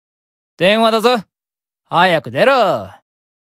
File:Mammon Call Notification (NB) Voice.ogg
Mammon_Call_Notification_(NB)_Voice.ogg